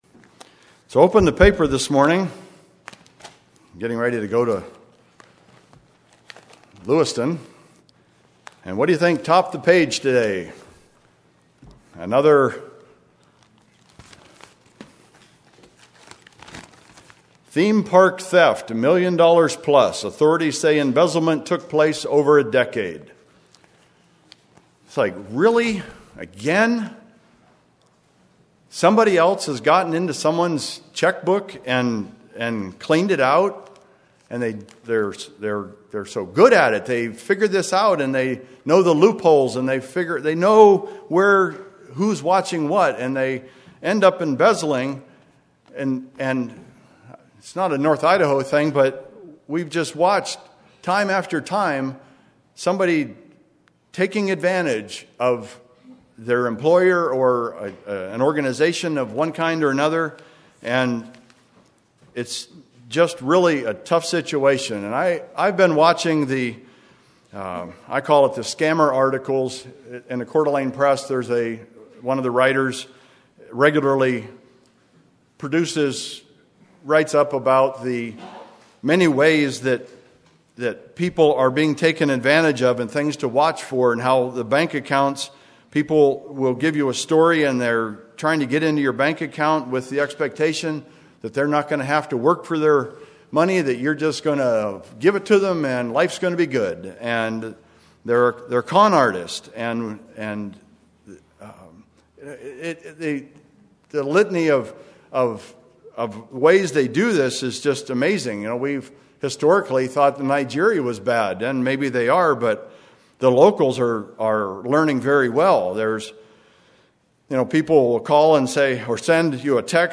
Sermons
Given in Spokane, WA Kennewick, WA Chewelah, WA